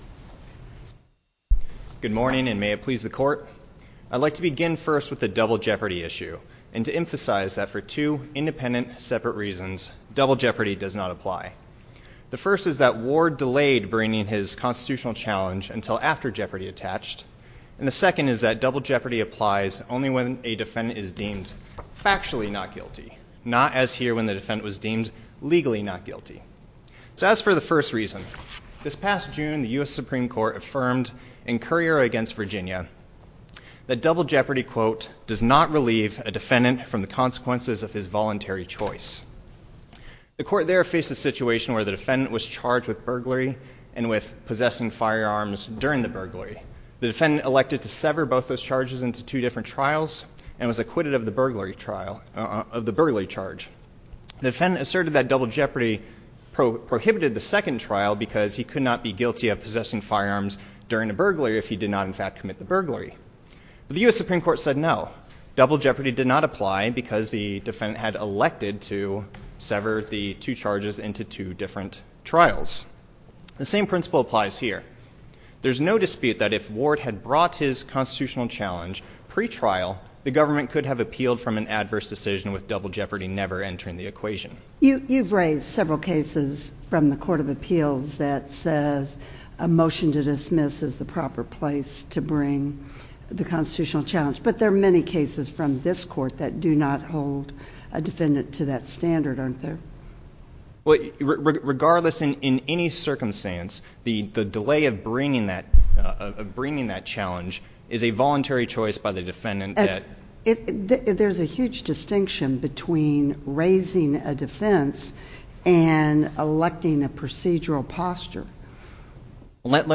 SC97006 State ex rel. PPG Industries Inc. v. The Honorable Maura B. McShane St. Louis County Challenge to exercise of jurisdiction over out-of-state company Listen to the oral argument: SC97006 MP3 file